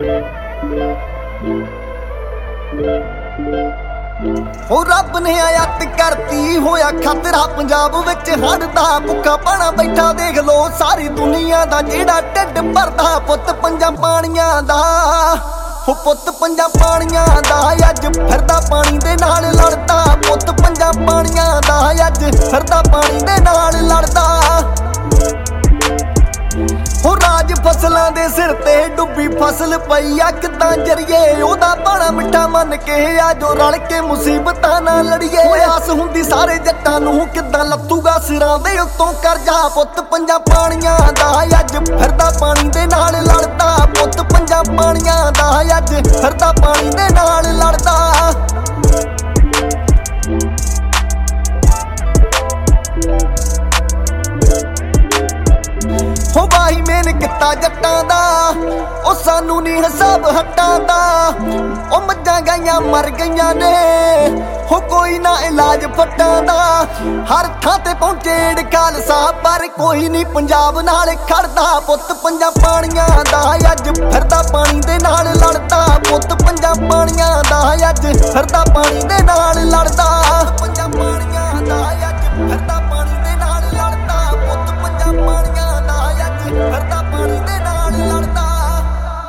Punjabi Bhangra
Indian Pop